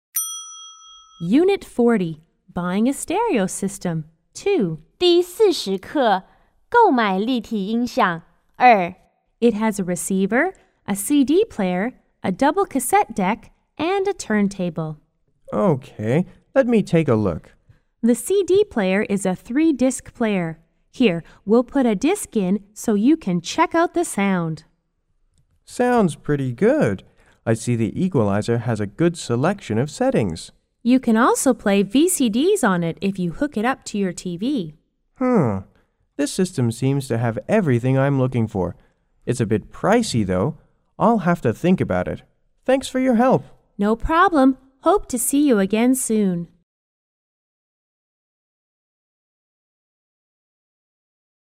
S= Salesperson C= Customer